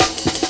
amen pt-1snare.wav